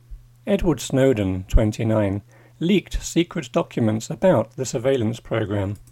DICTATION 9